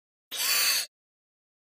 Servo Movement 1 Small Fast